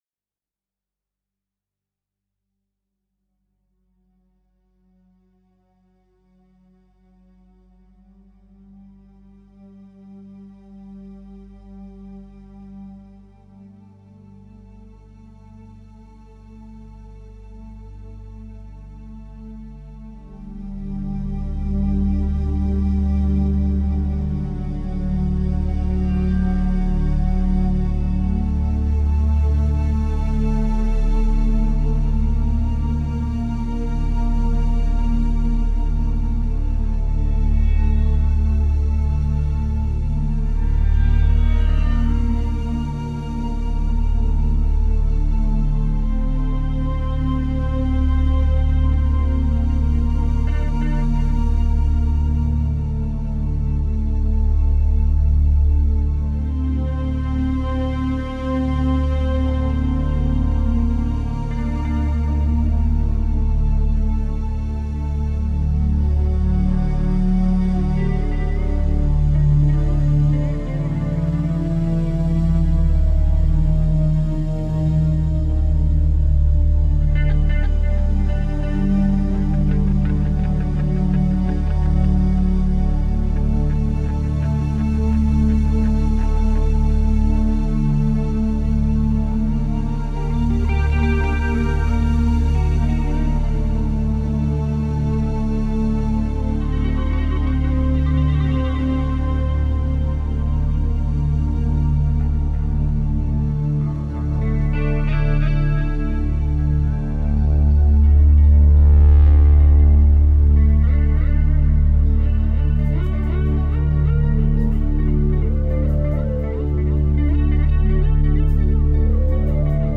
KARAWANE Der etwa 20 minütige Soundtrack mit dem Titel: KARAWANE entstand 1998 noch im damaligen MTM - Studio in Nordhorn. Schlagzeug
Blasinstrumente
Gitarren
Keyboards
Es handelt sich um einen Spontan- improvisation - alles live - während einer Studiosession zum Thema Wüste.
Die letzten 20 Sekunden des Soundtracks wurden nachträglich mit einem Finalizer bearbeitet, sodaß der Höreindruck entsteht, dass der gesamte Sound langsam in einem Telefonhörer entschwindet.